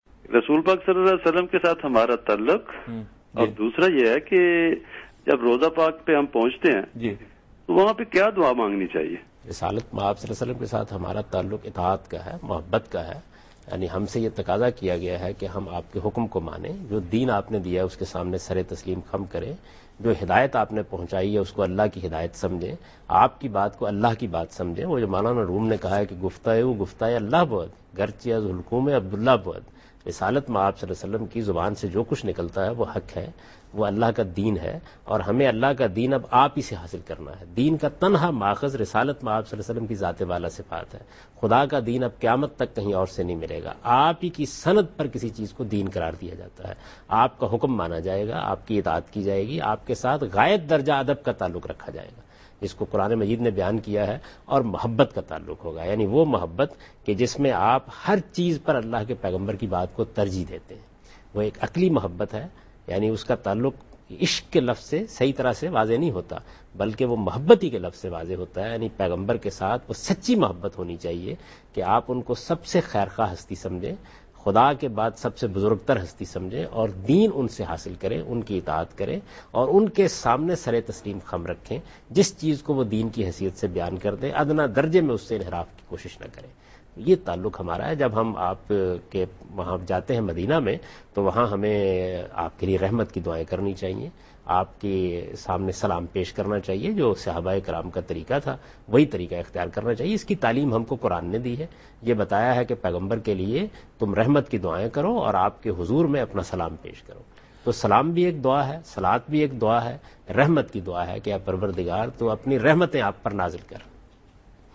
Javed Ahmad Ghamidi answer a question about "Our Relationship with Prophet Muhammad (sws)" in program Deen o Daanish on Dunya News.
حضرت محمد ﷺ سے ہمارا تعلق کیا ہے؟ جاوید احمد غامدی دنیا نیوز کے پروگرام دین و دانش میں ایک سوال کا جواب دے رہے ہیں۔